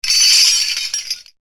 Spooky Halloween Sound Effects